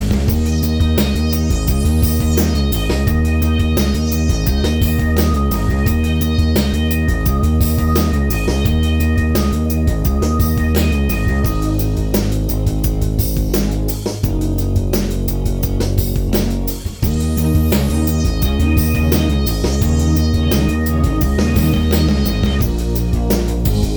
Minus Guitars Indie / Alternative 4:47 Buy £1.50